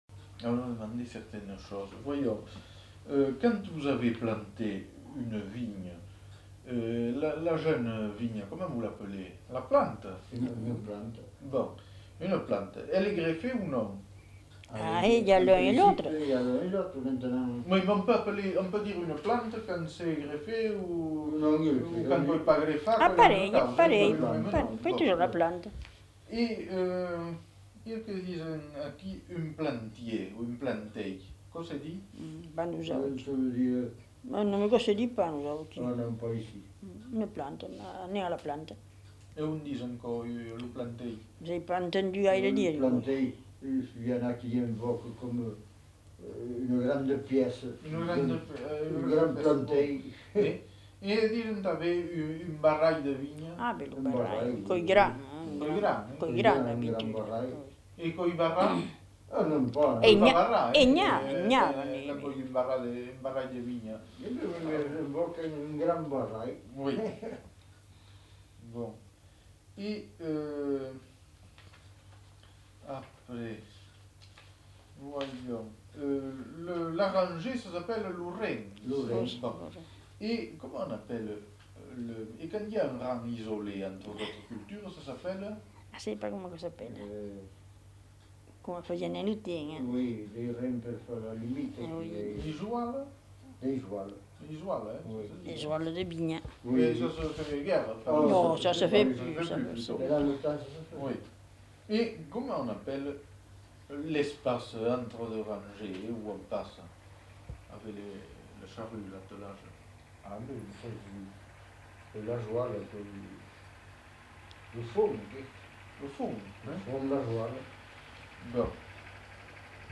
Nature du document : enquête
Type de son : mono
Qualité technique : bon
Lieu : Montpeyroux
Les deux informateurs (un homme et une femme) ne sont pas identifiés.